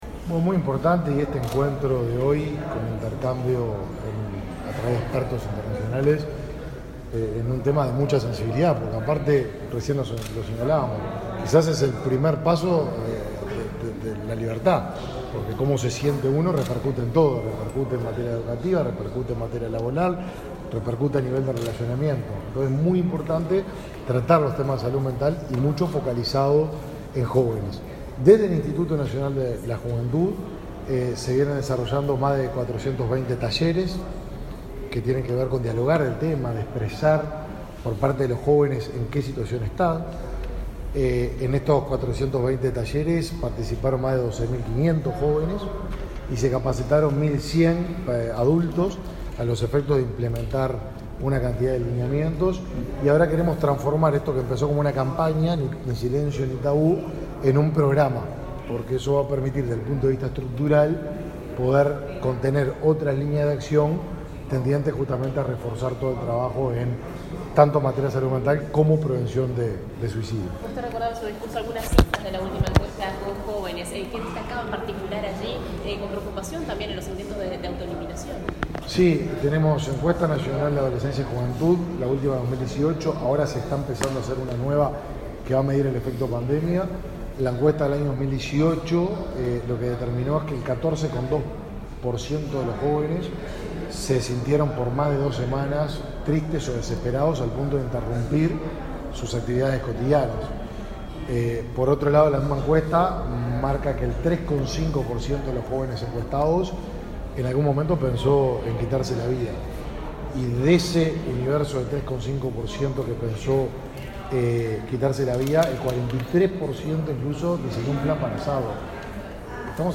Declaraciones del ministro de Desarrollo Social, Martín Lema
Declaraciones del ministro de Desarrollo Social, Martín Lema 07/11/2022 Compartir Facebook X Copiar enlace WhatsApp LinkedIn El ministro de Desarrollo Social, Martín Lema, participó en el seminario Salud Mental Adolescente: Aportes a la Estrategia Nacional, realizado este lunes 7 en la Torre Ejecutiva. Luego dialogó con la prensa.